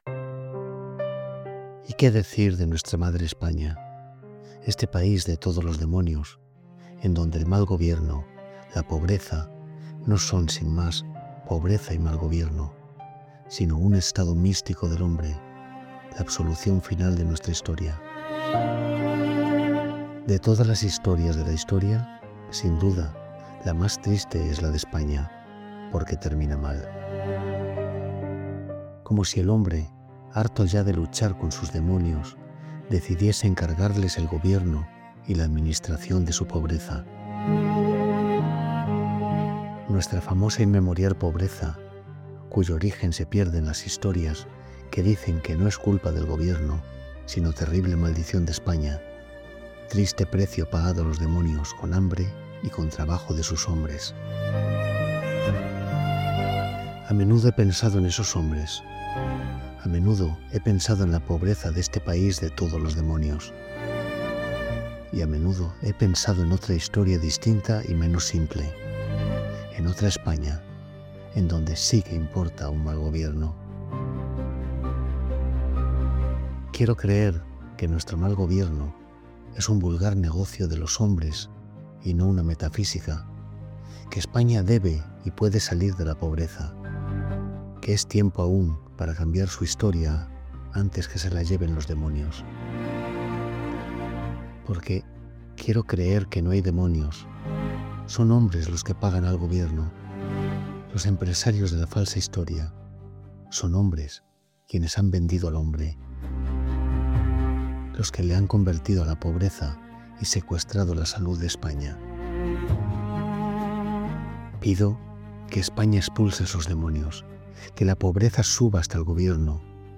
Gil-de-Biedma.-Apologia-y-confesion.-Music.mp3